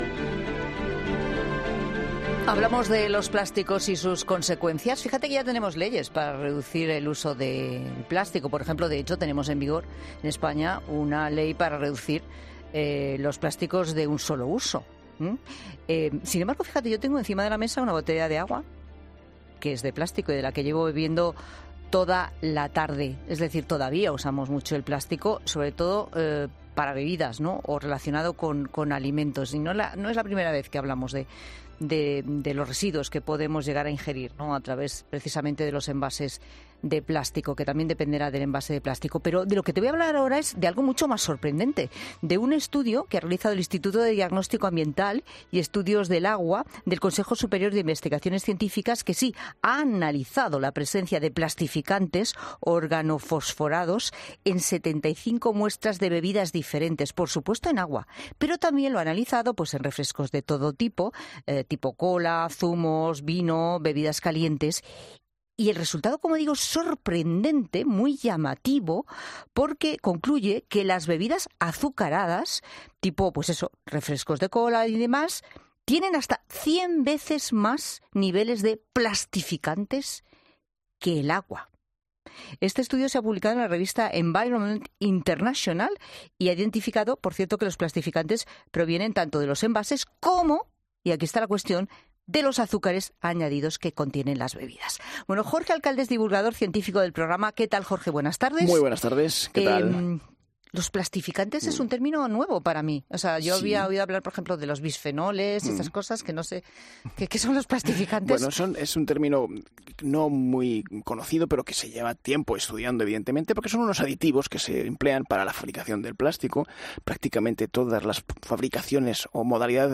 Un experto ha desvelado en 'La Tarde' dos elementos a los que tener en cuenta a la hora de comprar este tipo de bebidas y cómo pueden afectar a nuestra...